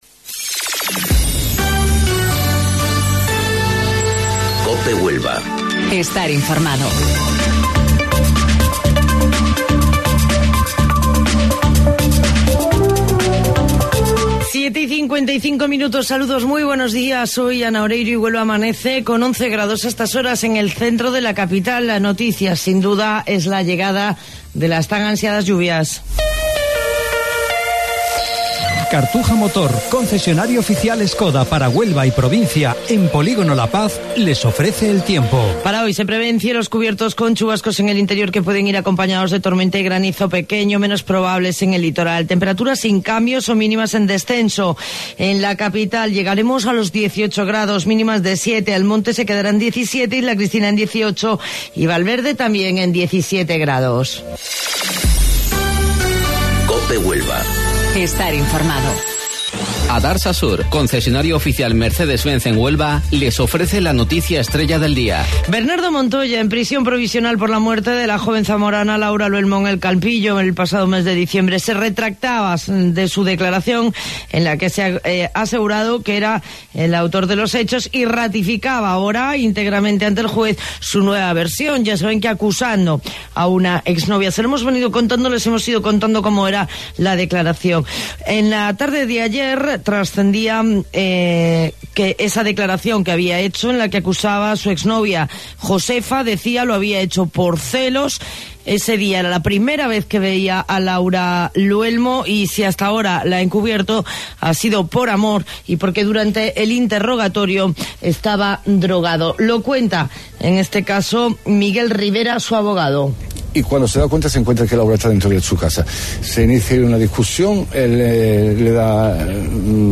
AUDIO: Informativo Local 07:55 del 5 de Abril